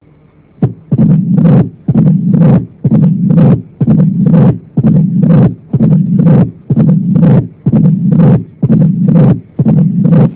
This Page contains some of the sounds of pathological Mitral and Aortic Valve Lesions.
Mitral Stenosis(MS) and Diastolic Murmur(DM)